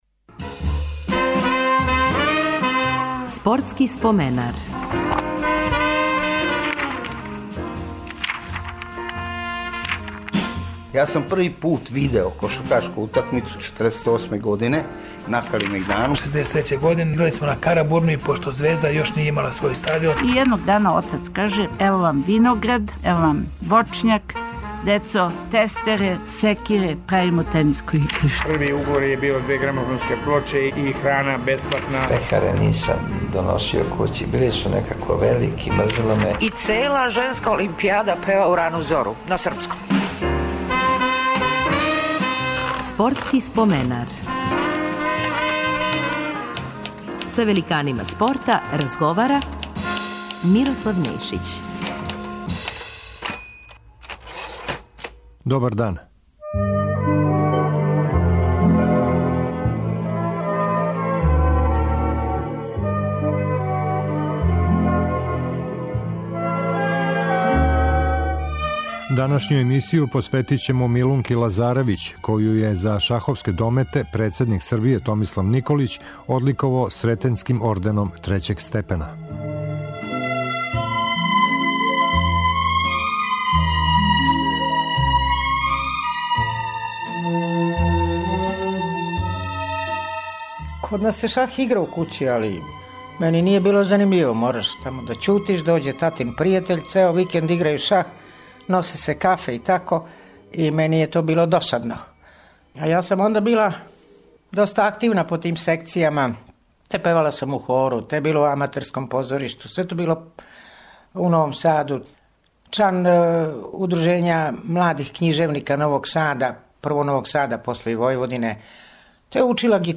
Тим поводом репризираћемо део разговора са прослављеном шахисткињом. Биће то сећања на њене почетке бављења овом игром у Новом Саду.